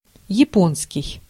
Ääntäminen
France: IPA: [ʒa.po.nɛ]